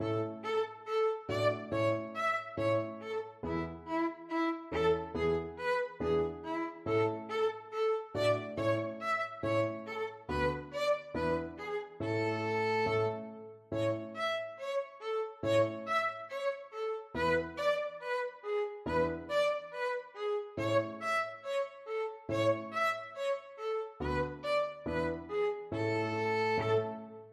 4/4 (View more 4/4 Music)
Fast = c. 140